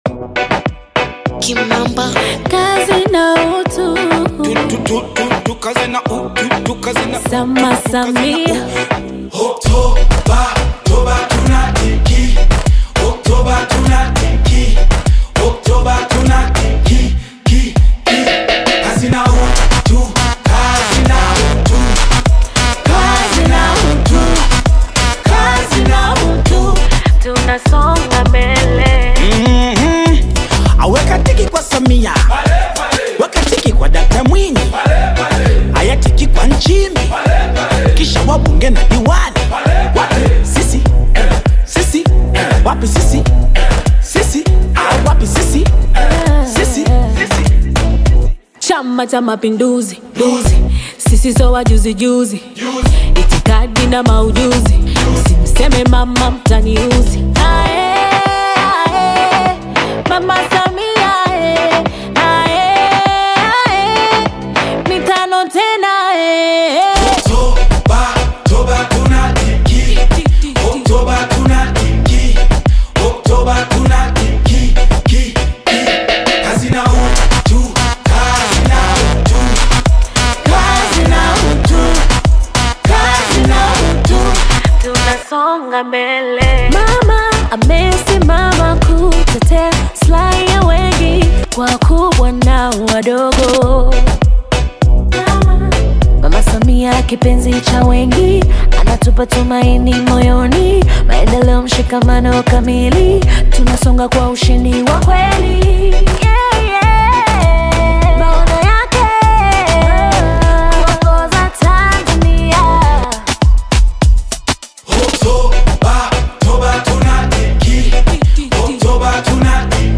politically inspired anthem